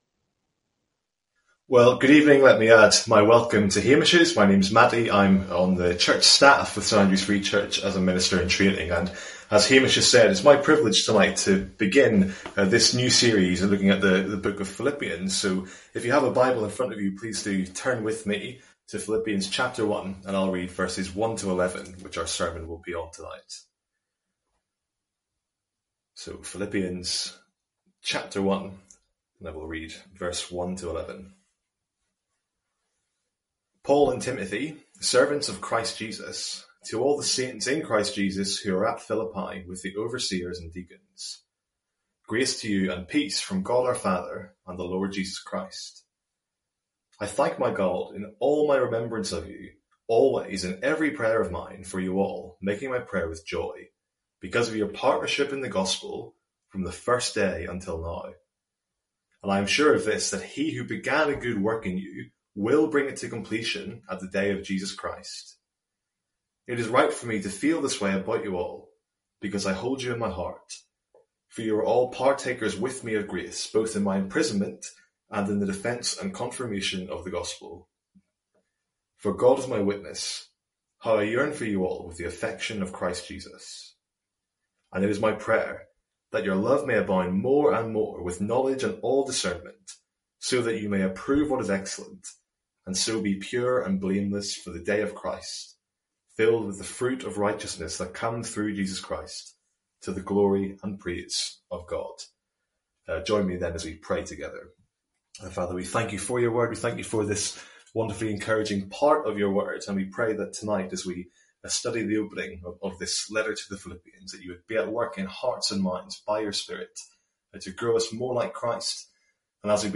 Sermons | St Andrews Free Church
From our evening service in Philippians.